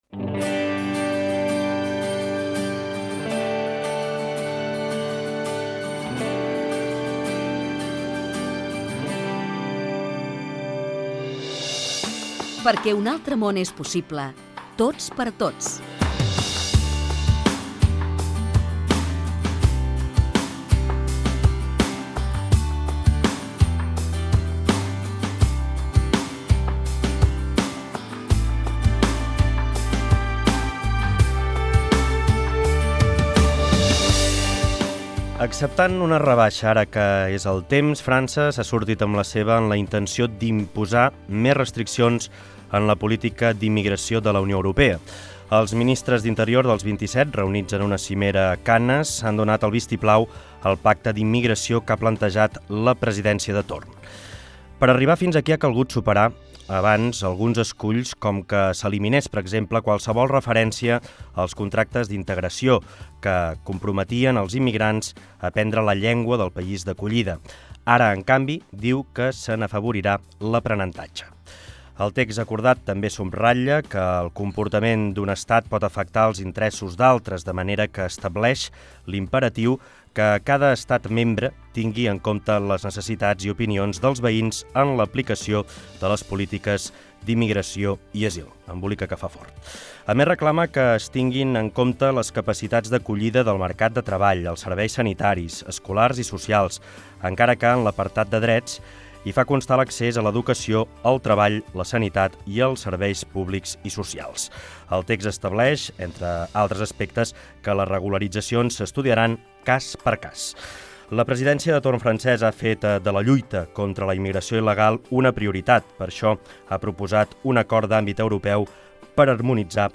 Careta, presentació del programa sobre les restriccions que vol fer França a les polítiques d'immigració
Divulgació